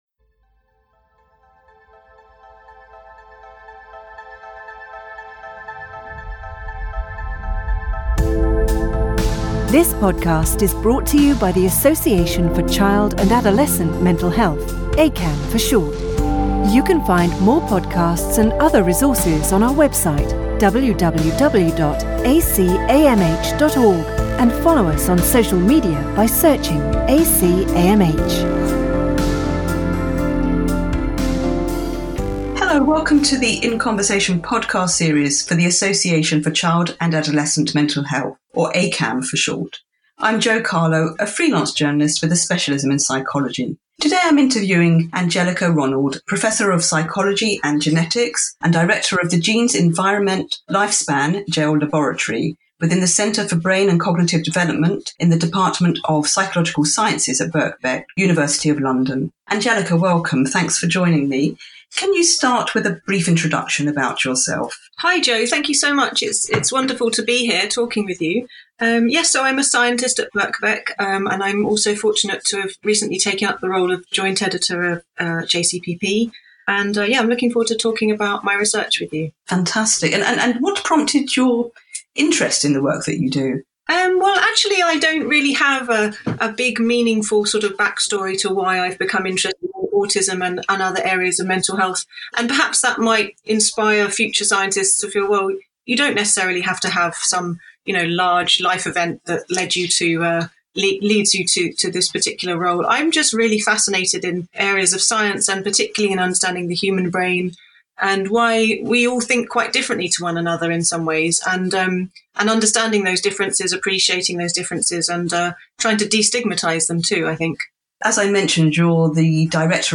In a wide ranging interview